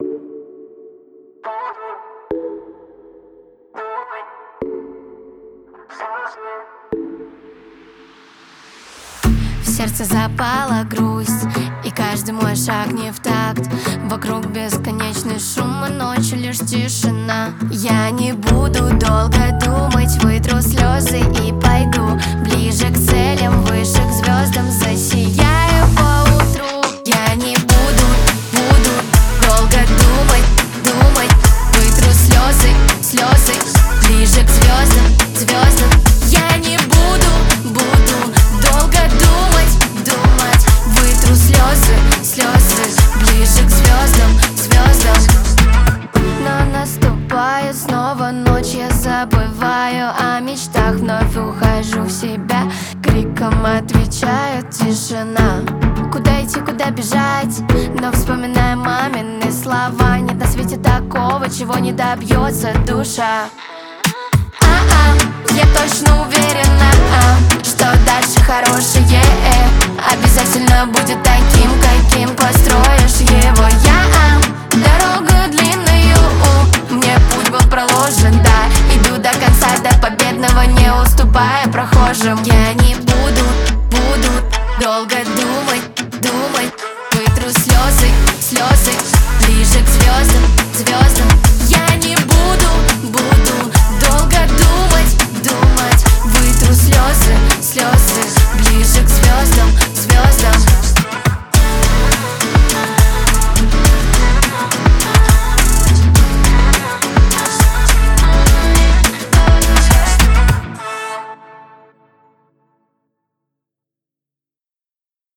мелодичный вокал